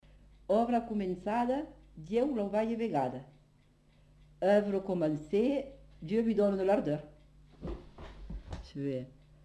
Lieu : Cathervielle
Type de voix : voix de femme
Production du son : récité
Classification : proverbe-dicton